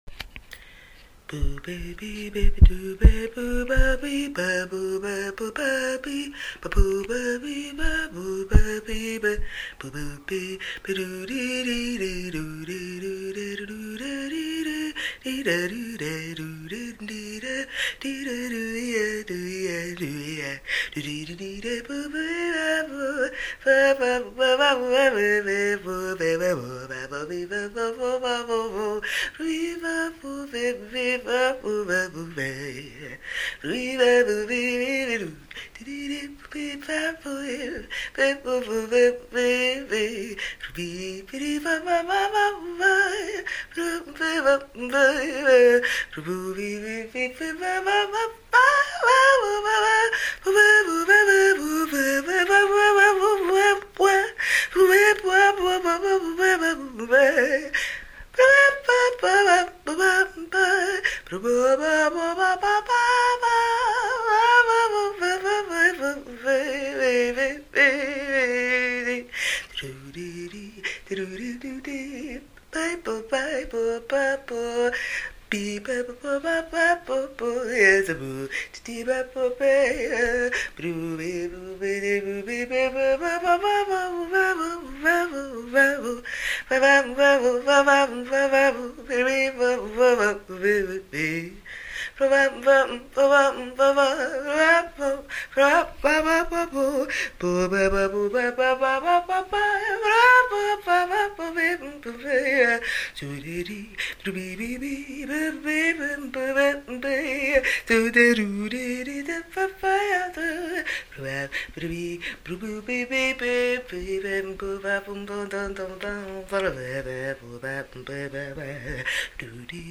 Today, I sing the blues.